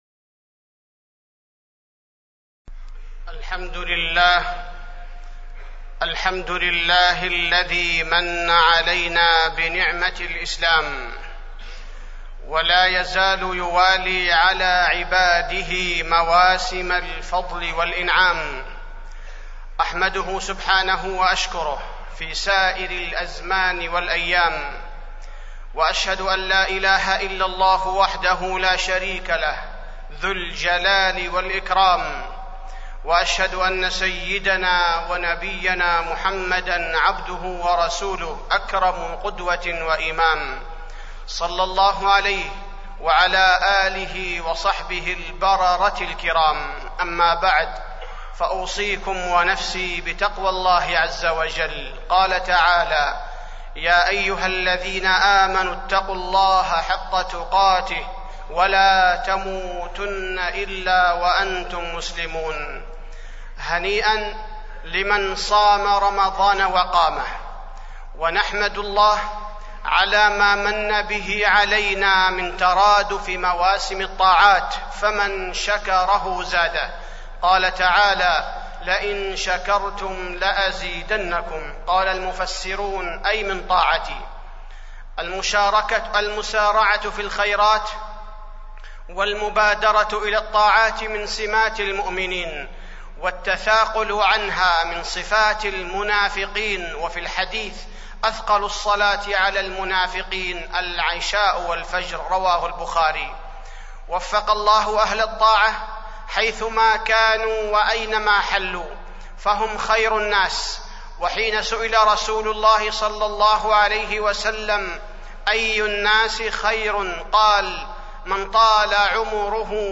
تاريخ النشر ٩ شوال ١٤٢٦ هـ المكان: المسجد النبوي الشيخ: فضيلة الشيخ عبدالباري الثبيتي فضيلة الشيخ عبدالباري الثبيتي أهل الطاعة